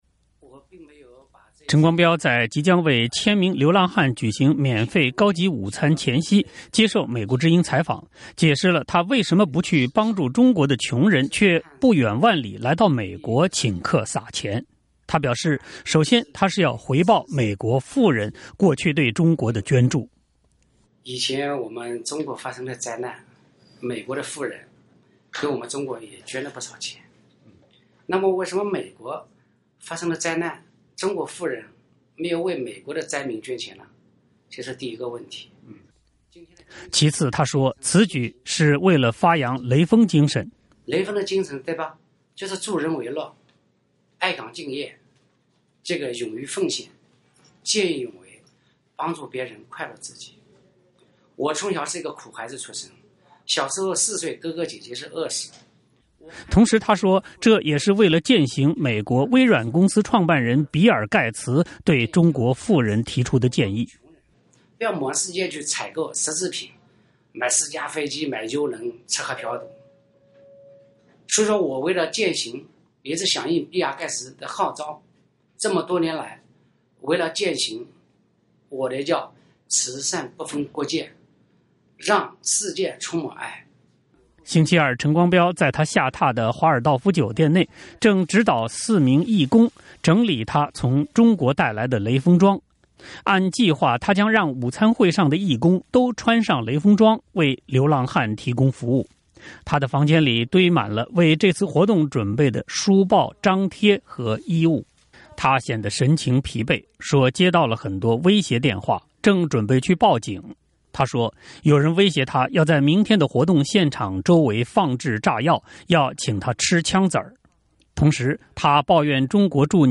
陈光标在即将为千名流浪汉举行免费高级午餐前夕接受美国之音采访，解释了他为什么不去帮助中国的穷人，却不远万里来到美国请客撒钱。